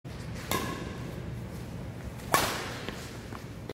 알림음(효과음) + 벨소리
알림음 8_셔틀콕2.mp3